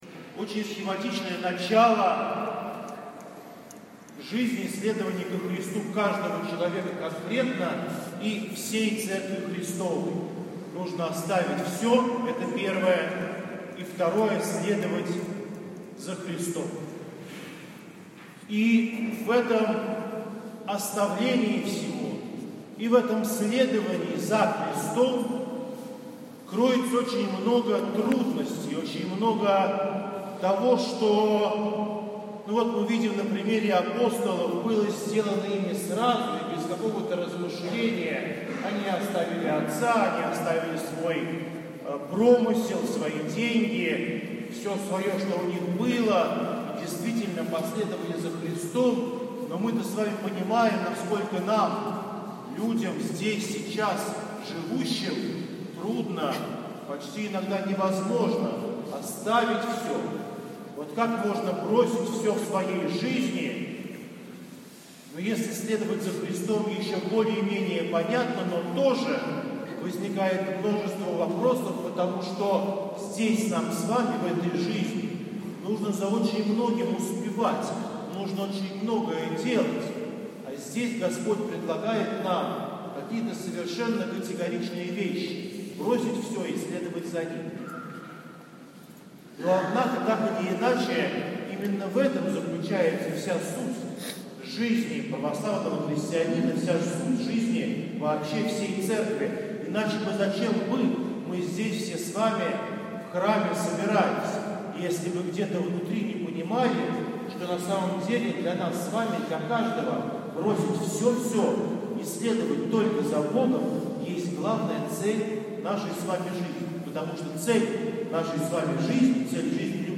всенощное бдение 29 июня 2019г.